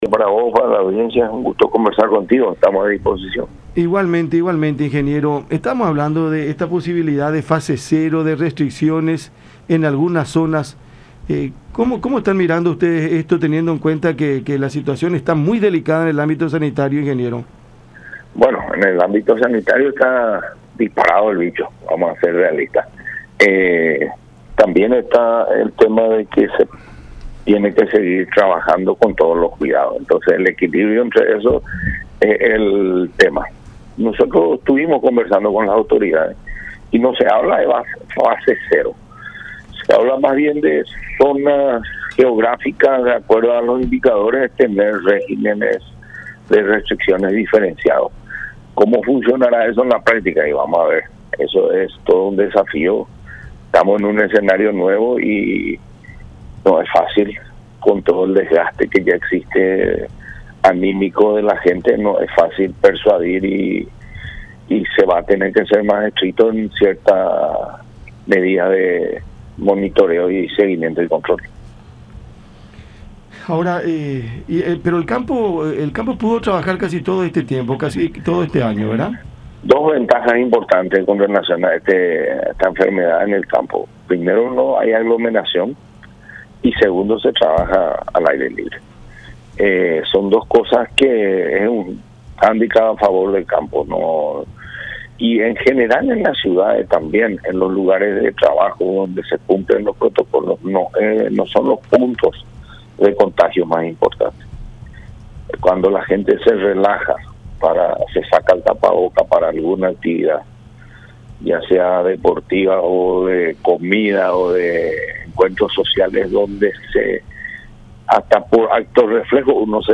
en diálogo con La Unión.